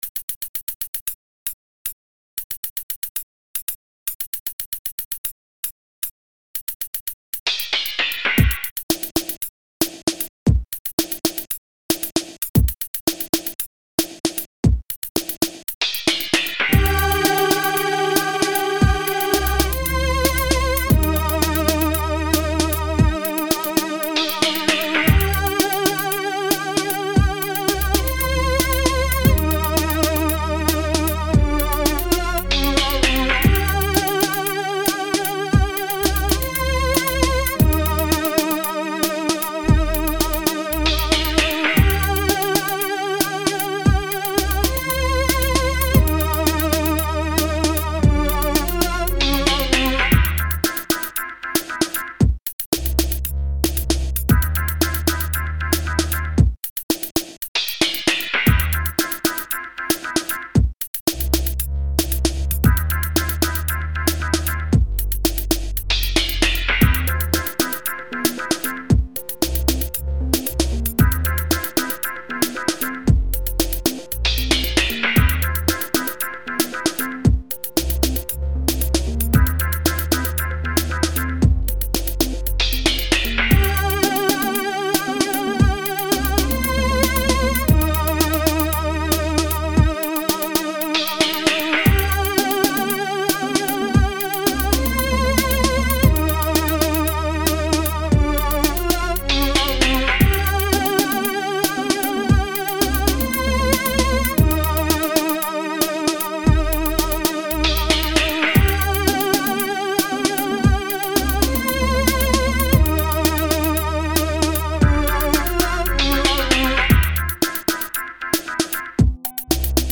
nothing-is-true-instrumental.mp3